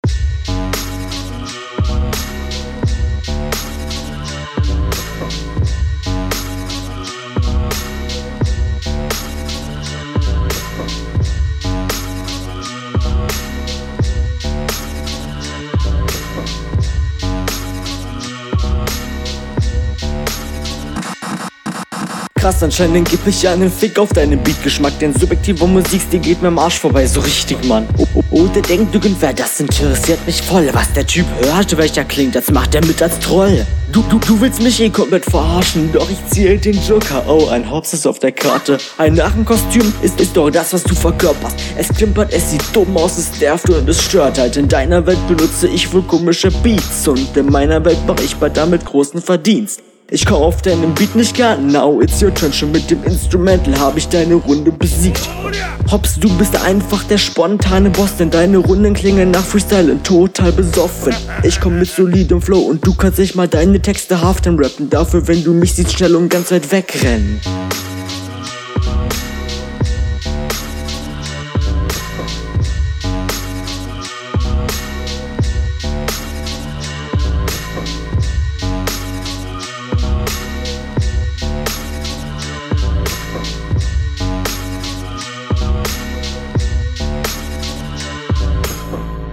Flowlich ist das deutlich stärker als die HR2, viel angenehmerer Stimmeinsatz.